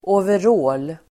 Uttal: [åver'å:l]